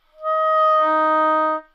双簧管单音（弹得不好） " 双簧管D4不好的攻击空气
描述：在巴塞罗那Universitat Pompeu Fabra音乐技术集团的goodsounds.org项目的背景下录制。
Tag: 好声音 单注 多重采样 纽曼-U87 Dsharp4 双簧管